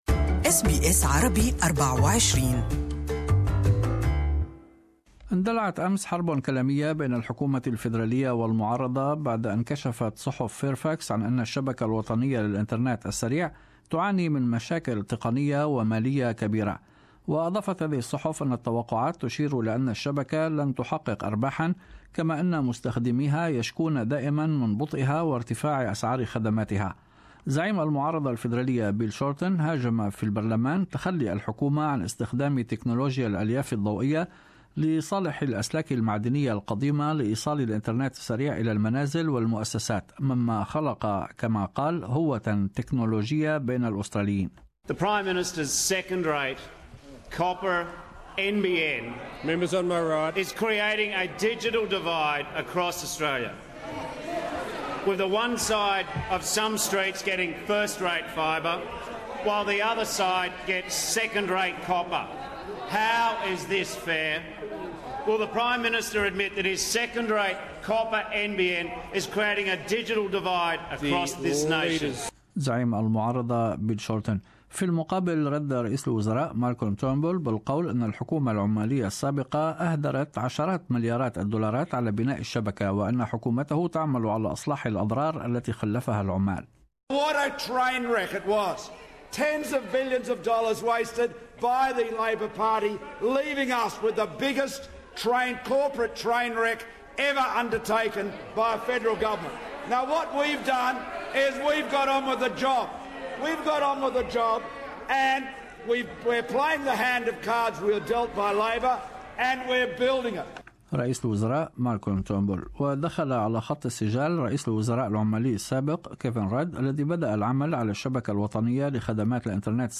In this bulletin ...